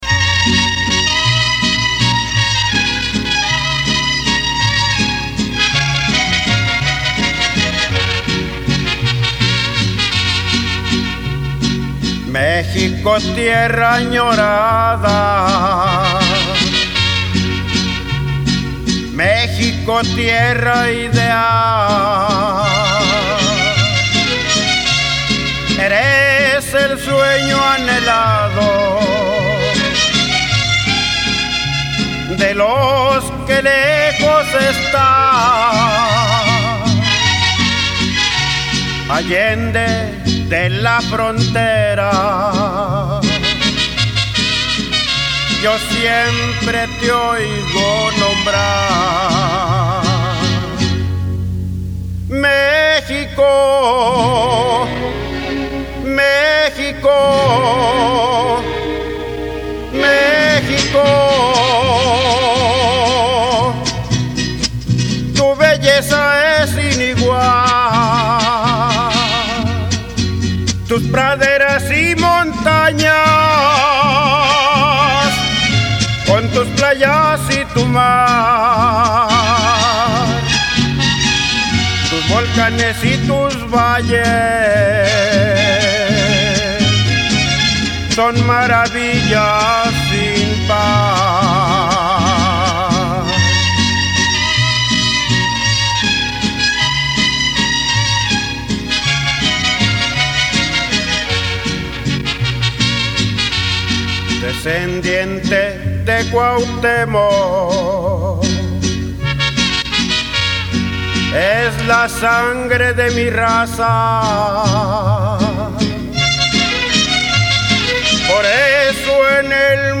musica folklorica real